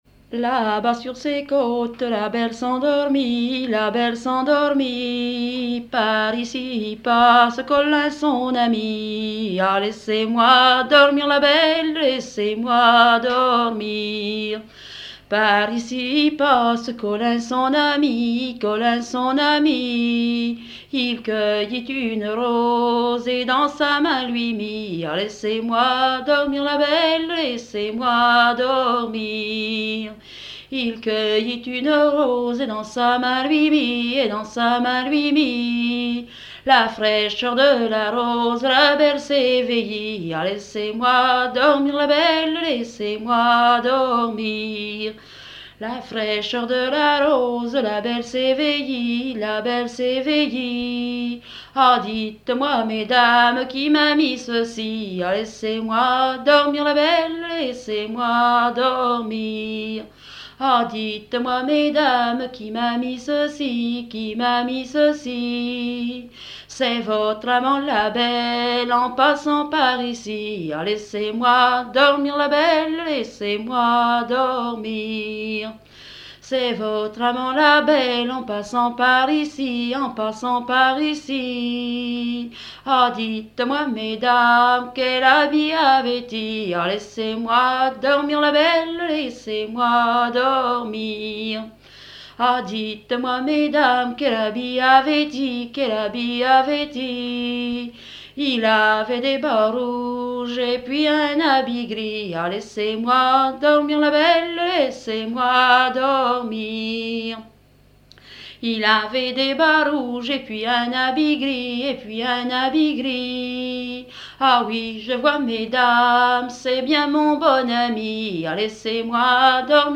danse : ronde
Pièce musicale inédite